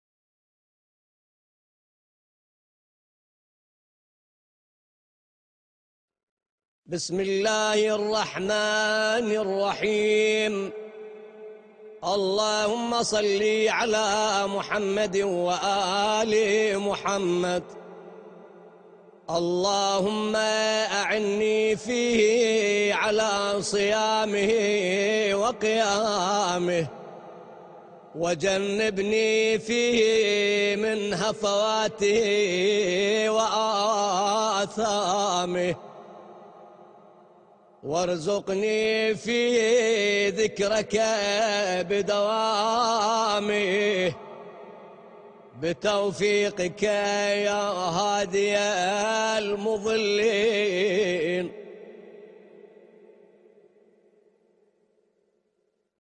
دعای روز هفتم ماه مبارک رمضان